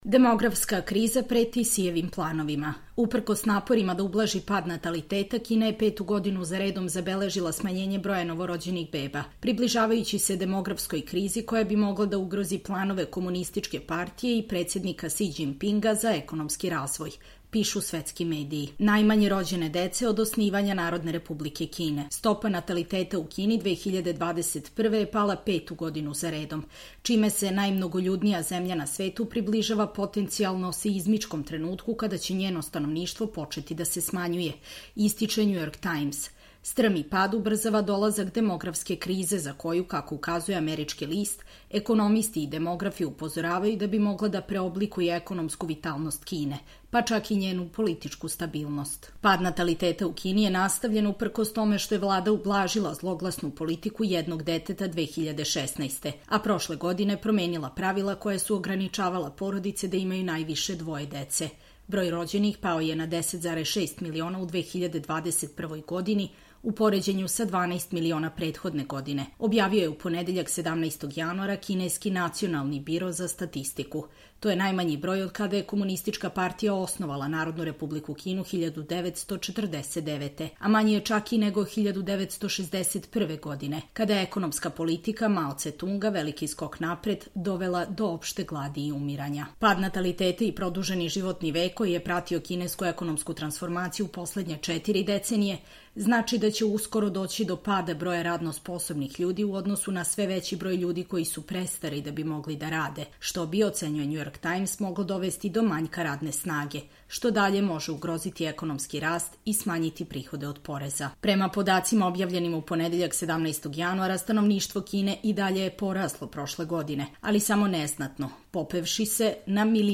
Čitamo vam: Demografska kriza preti Sijevim planovima